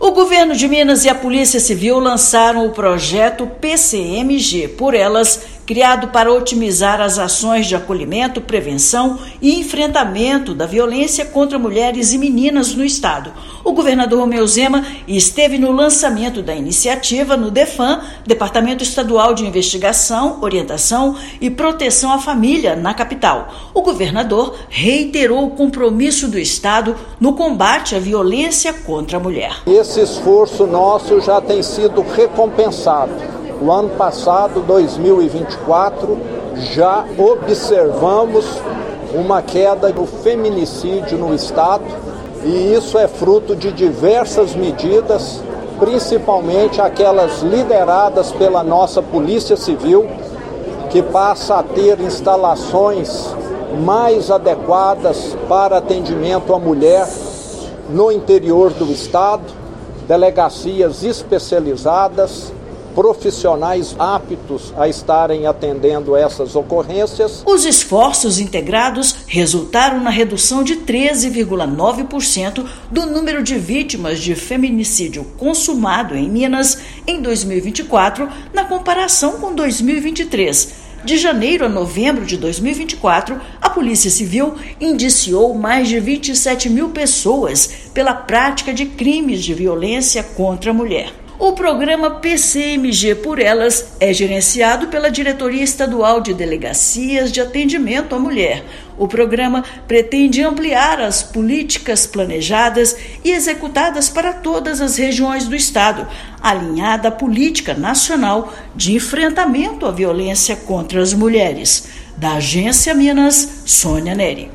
Iniciativa visa ampliar ações planejadas e executadas pela Polícia Civil para todo o estado, garantindo a proteção de mulheres e meninas. Ouça matéria de rádio.